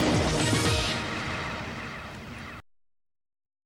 The sport sting.